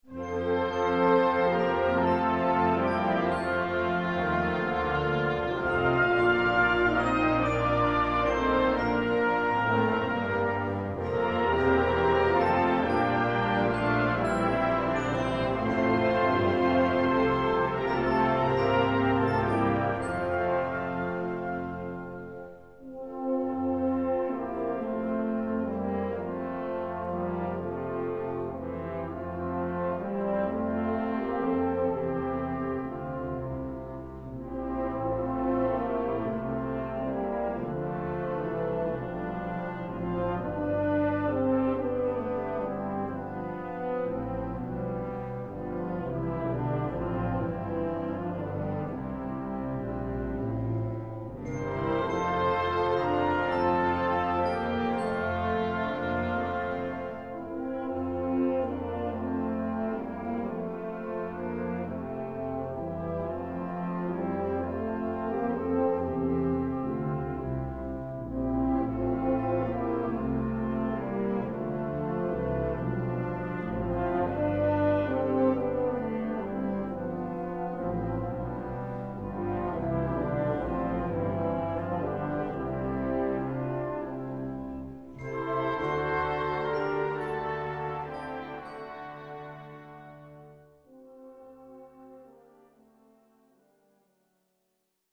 Gattung: Feierliche Musik
Besetzung: Blasorchester